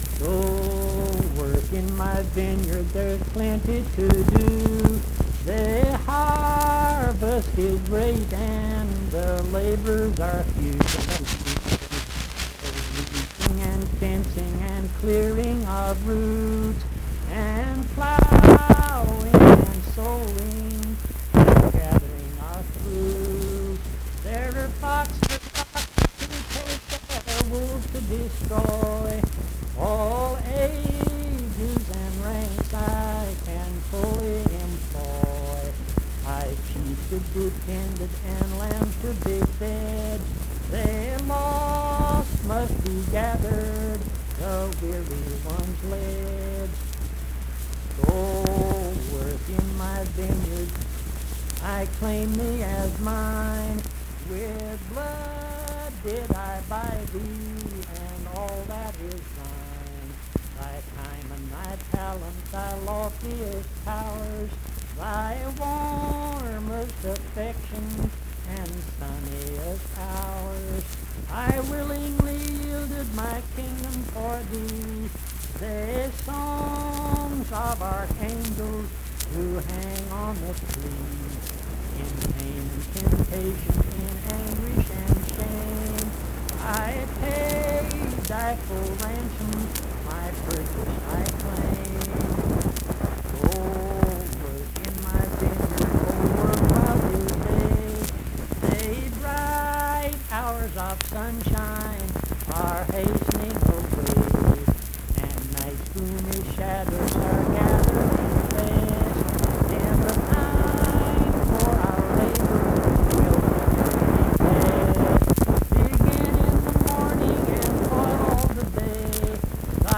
Accompanied (guitar) and unaccompanied vocal music
Performed in Mount Harmony, Marion County, WV.
Hymns and Spiritual Music
Voice (sung)